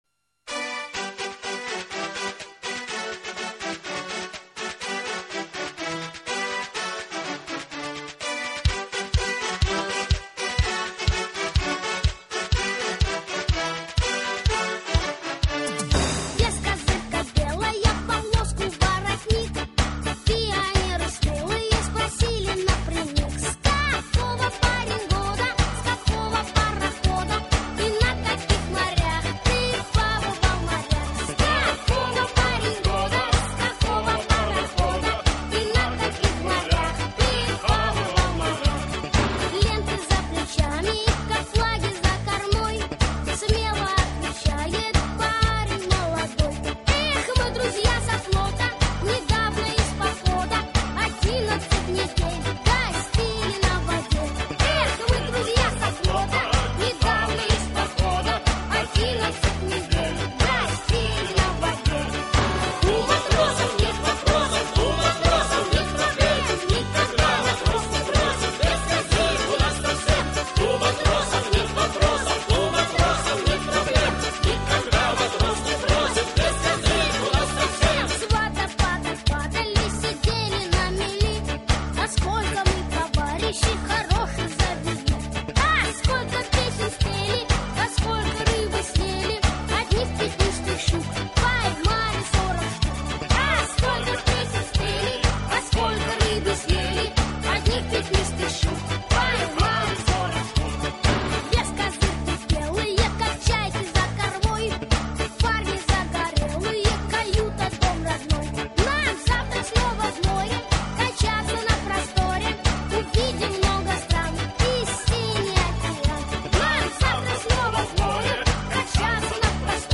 Главная / Computer & mobile / Мелодии / Патриотические песни
Загрузить Энергичное, веселое исполнение Назад в раздел